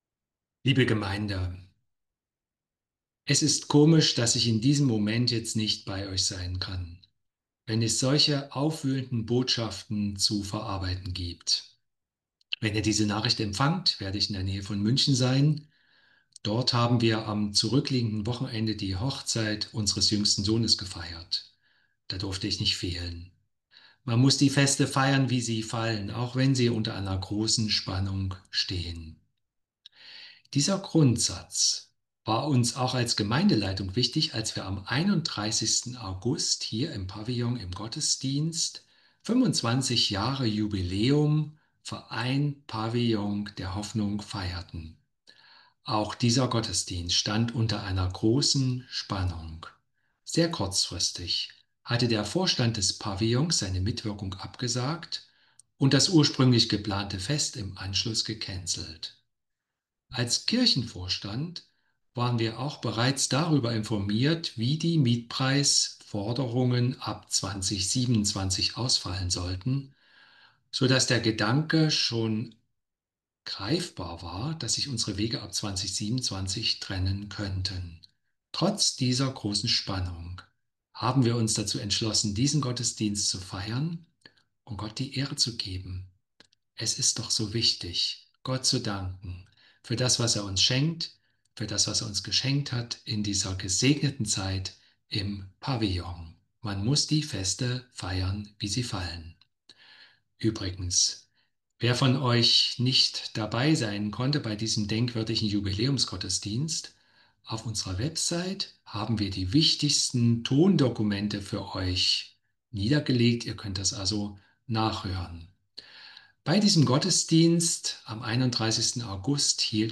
Hier stellen wir Dir die Audiodateien aus dem Gottesdienst zur Verfügung.